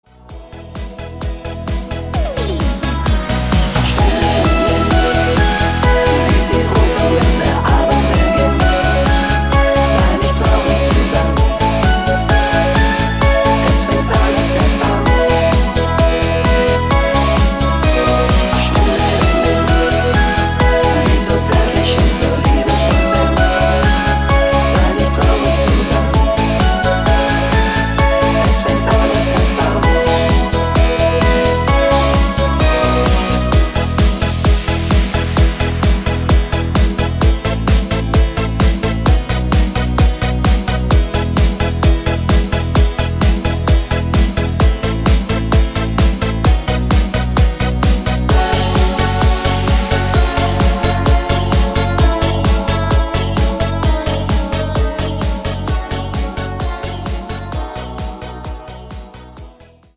Oktave Männerstimme geeignet